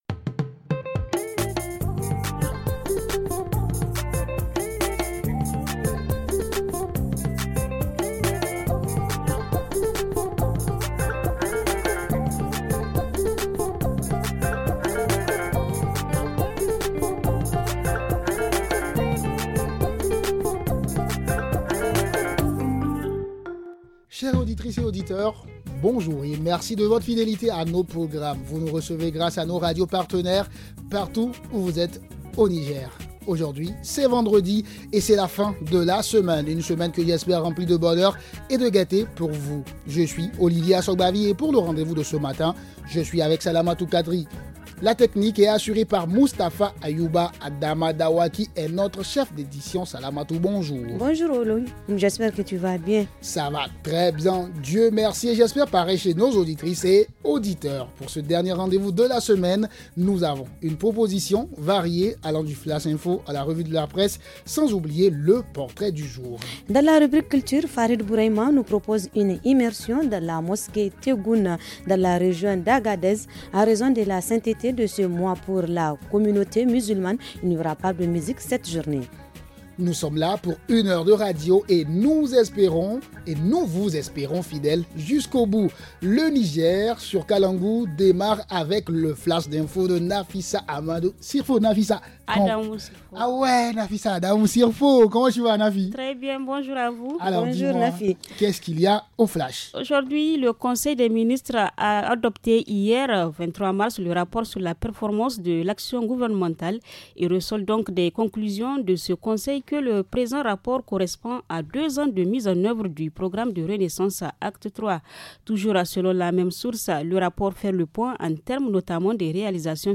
-Entretien